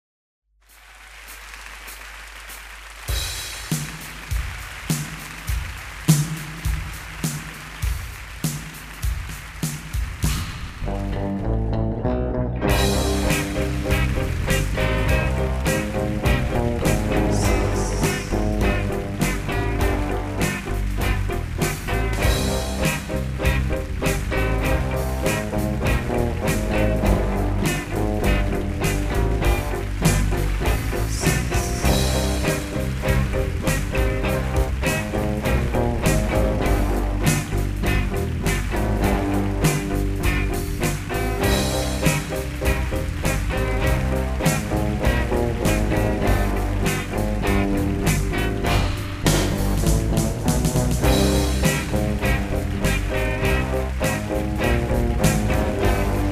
Je remarque qu'on est tous (enfin nous trois, enfin chez moi faut bien chercher) d'accords sur un rythme blues assez sympa, et tout le monde a rajouté des petits bruitages maison !!!
Le morceau est assez long mais pas ennuyeux du tout, j'ai même trouvé la fin un peu brutale. 4/5
Rendu sonore : Peut être un tout petit manque de reverb pour les drums, mais ça passe très bien. L'enregistrement voix peak un tout petit peu. ensuite je trouve ça nickel ! 4/5
J'adore la basse à 52".
Une musique d'ambiance très cool dans l'ensemble.
Juste dommage que la trompette du début se coupe un peu brusquement à chaque fois !